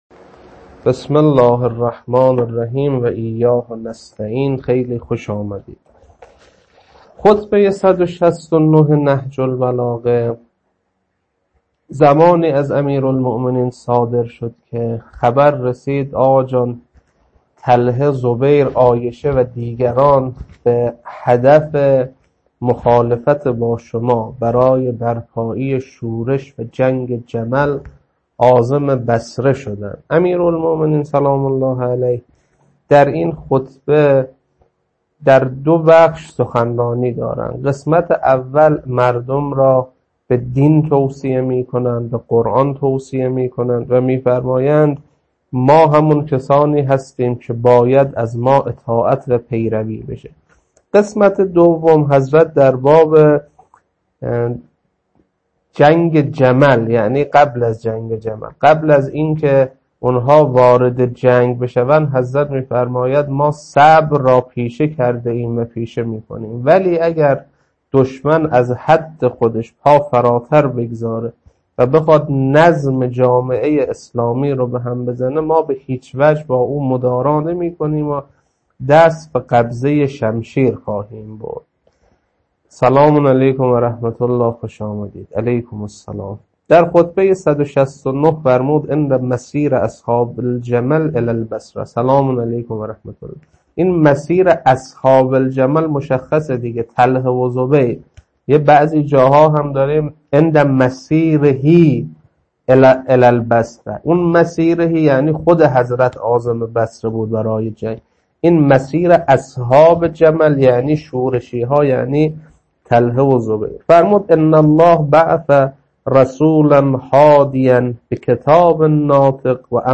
خطبه 169.mp3
خطبه-169.mp3